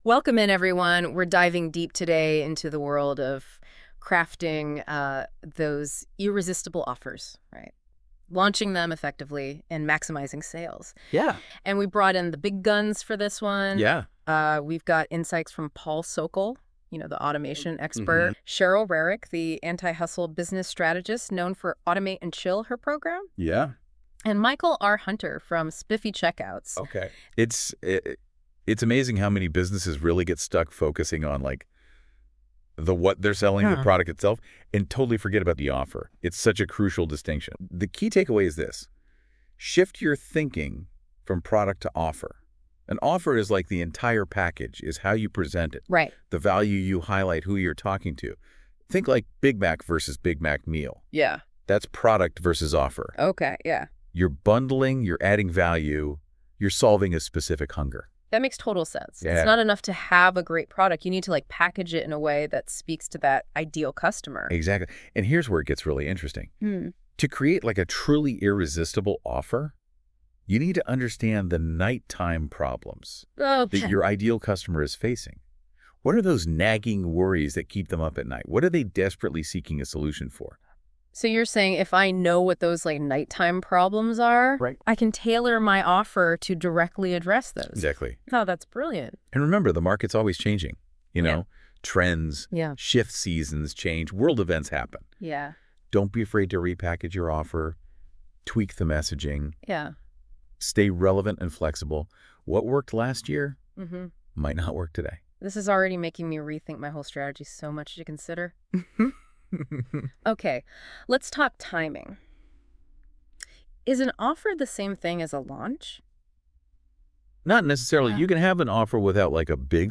Podcast: Yes, an actual audio podcast with two AI generated speakers discussing the subject matter you supplied. Here’s an example of what that podcast can look like – this is based off of the demo we did with the webinar on crafting offers .